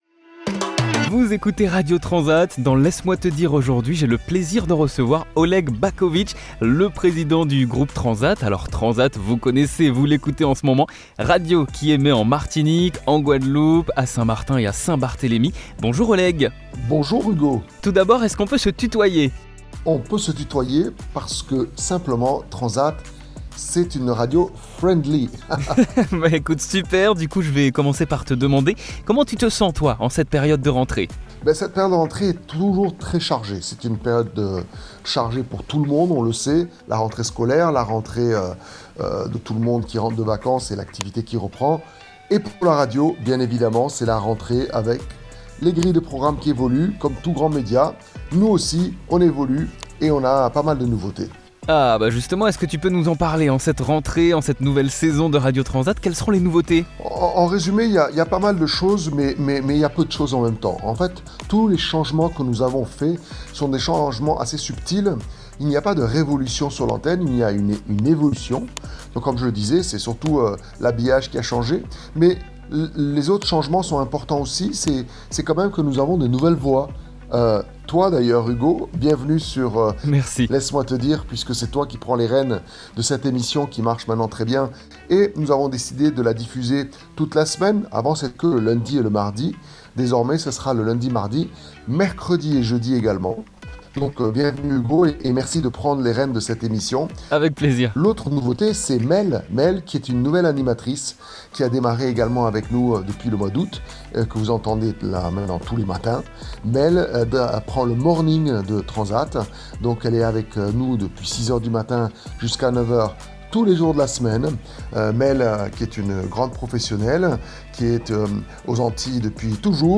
Emission spéciale rentrée
Dans cette interview